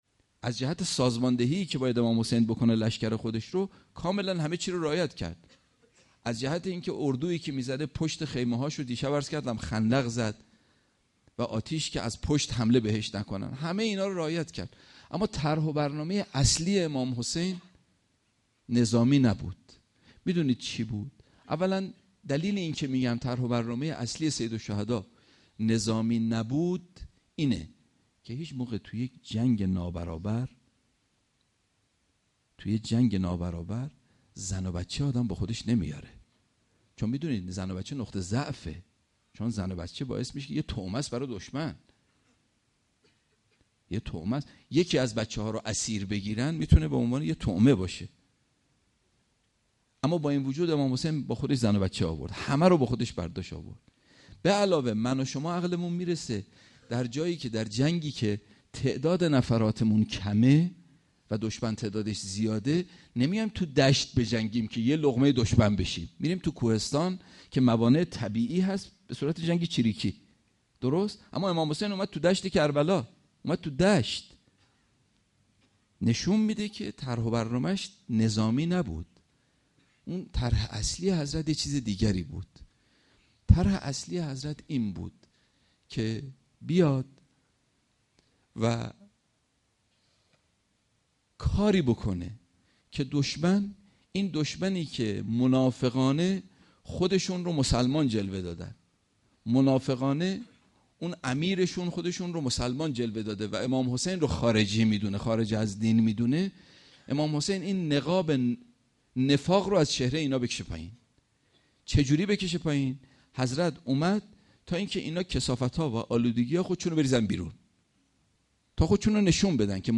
سخنرانی شب هشتم